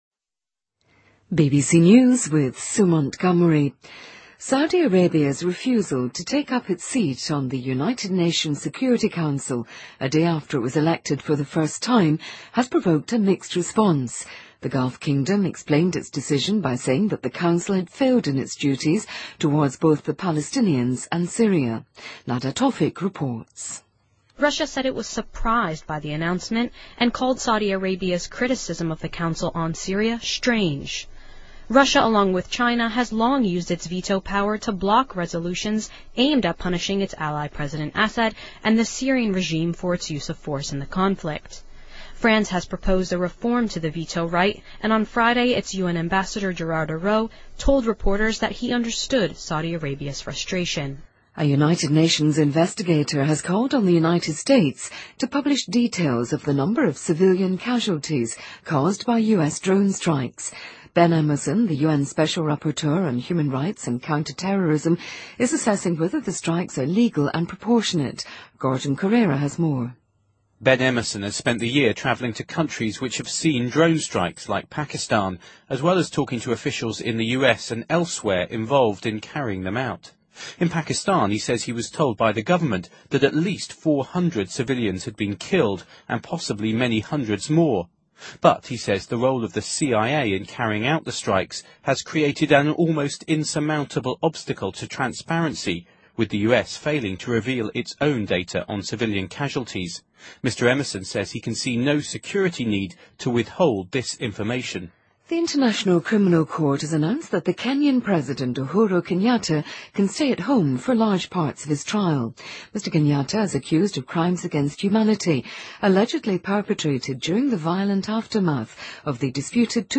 BBC news,沙特拒绝联合国安理会席位